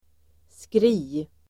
Uttal: [skri:]